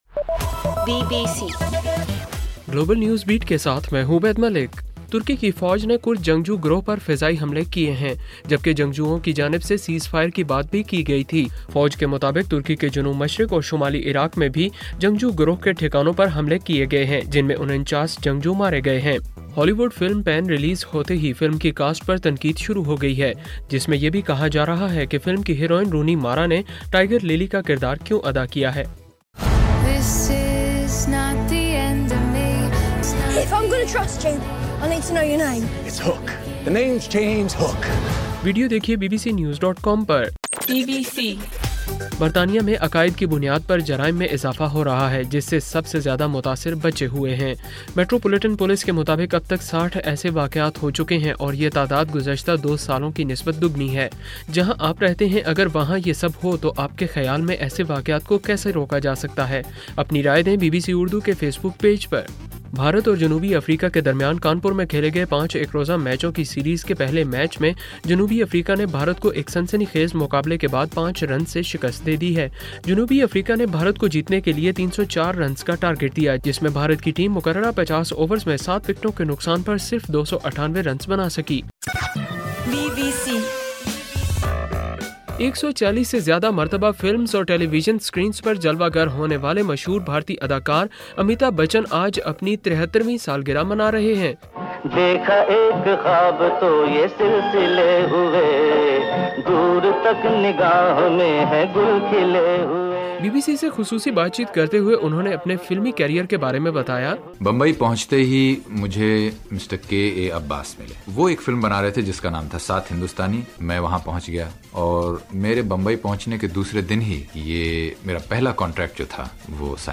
اکتوبر 11: رات 12 بجے کا گلوبل نیوز بیٹ بُلیٹن